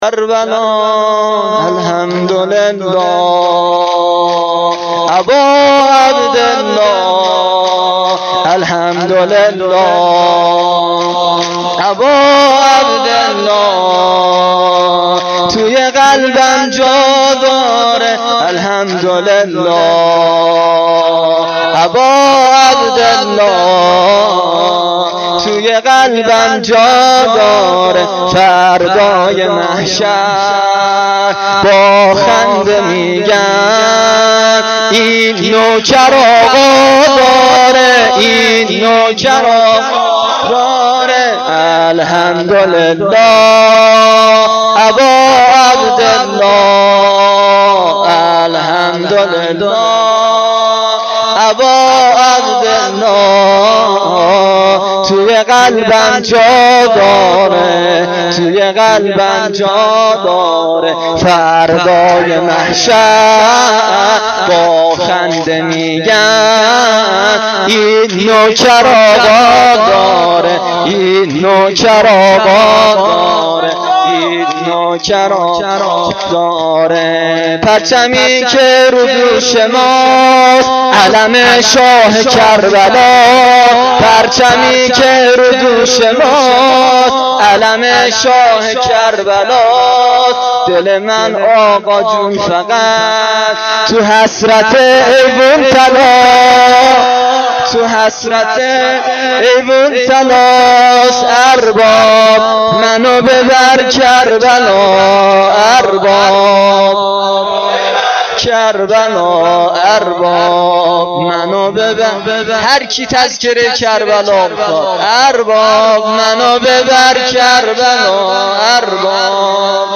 شور شب هشتم محرم1393